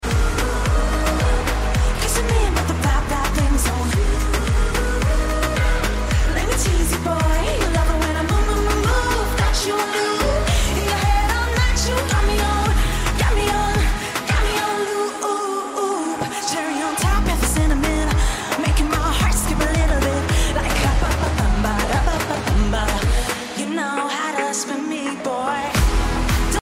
an energetic song